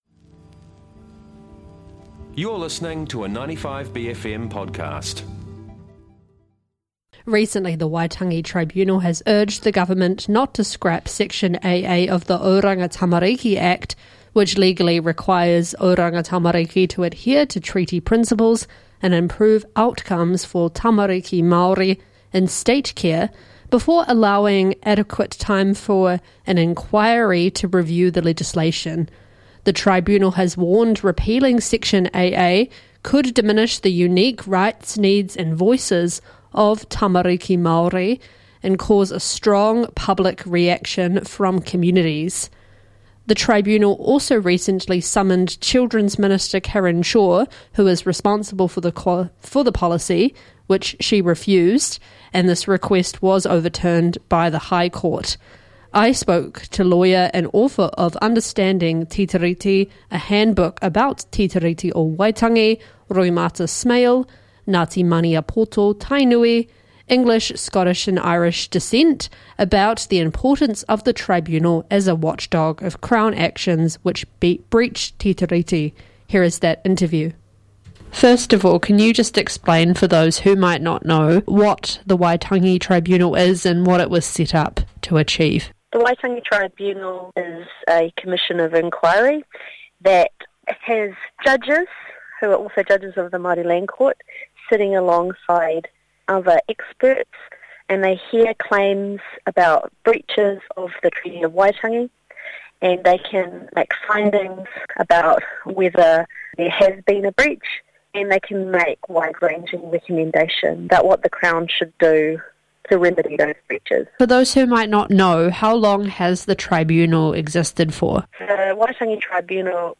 The Waitangi Tribunal w/ Lawyer